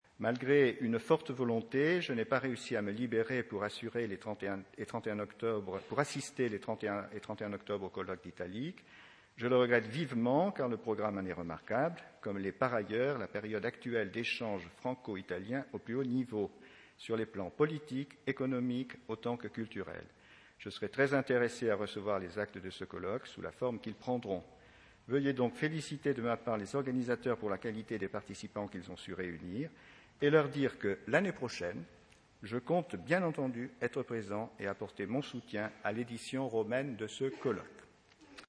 Le colloque annuel de l’association Italiques, « Paris-Rome : trajectoires de deux capitales culturelles », s’est tenu les 30 et 31 octobre 2012 dans les salons de l’Hôtel de Ville de Paris.
Allocutions d’ouverture